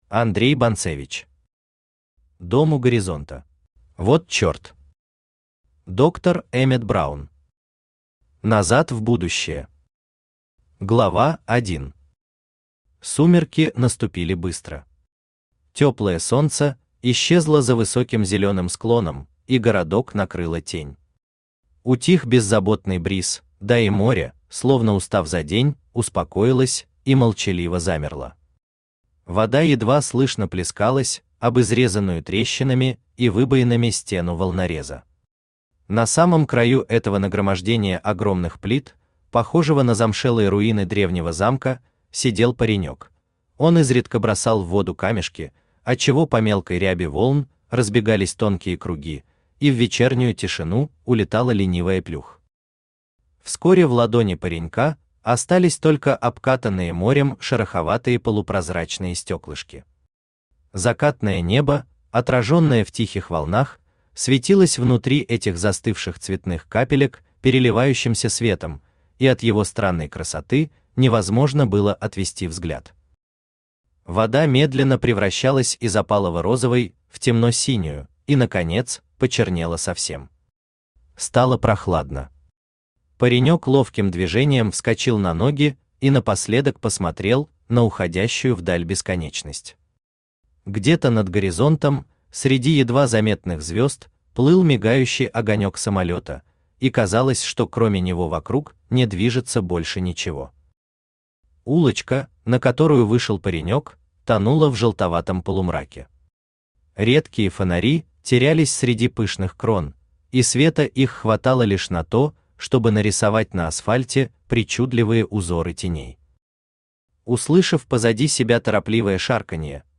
Аудиокнига Дом у горизонта | Библиотека аудиокниг
Aудиокнига Дом у горизонта Автор Андрей Бонцевич Читает аудиокнигу Авточтец ЛитРес.